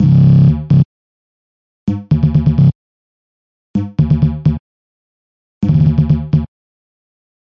和声c短的旋律循环
描述：我在G1的128bpm做了一个和声的短旋律循环
Tag: 循环 harmonyc 旋律 合成器 顺序 进展